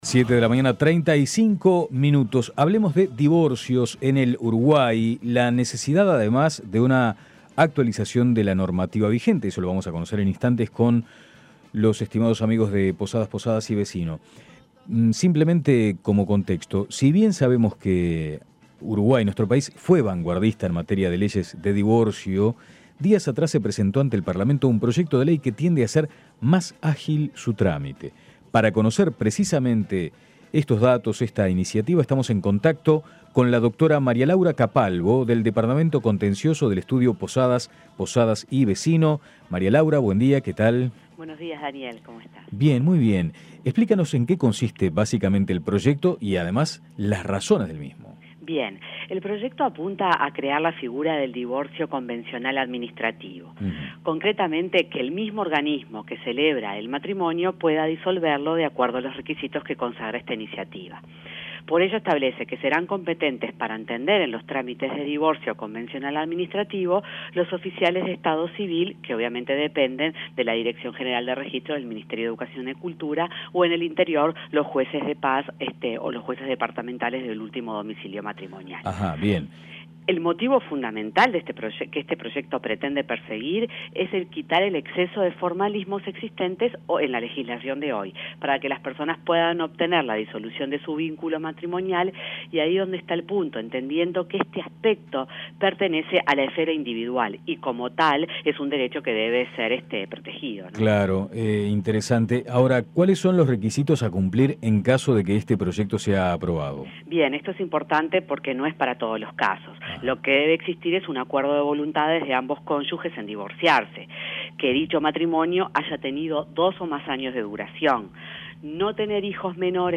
Explíquenos en qué consiste el proyecto y el porqué del mismo.